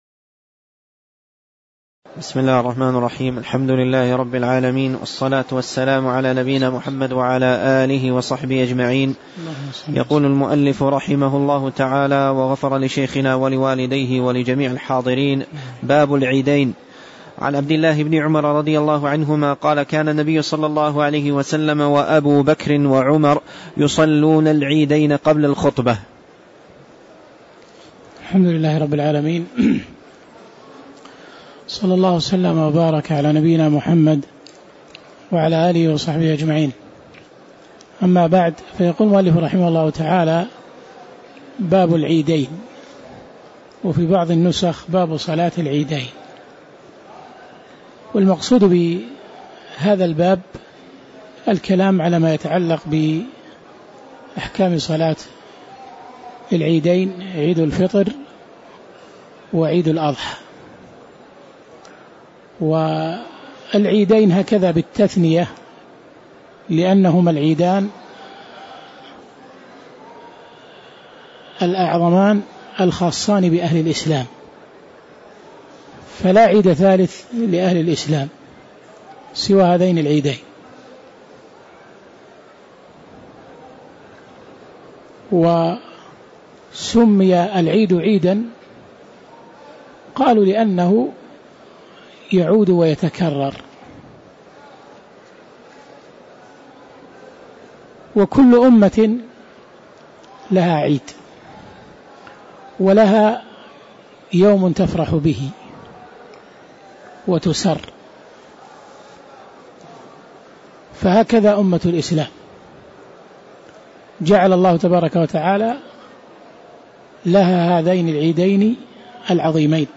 تاريخ النشر ٤ رجب ١٤٣٧ هـ المكان: المسجد النبوي الشيخ